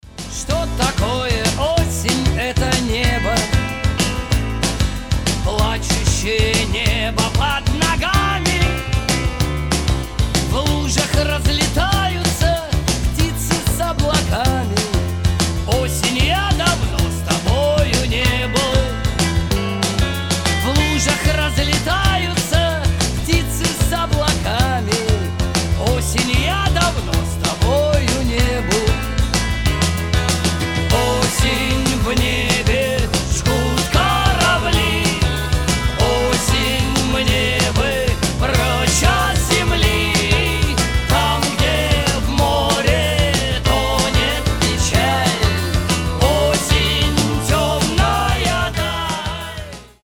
• Качество: 320, Stereo
гитара
мужской вокал
печальные
русский рок
ностальгия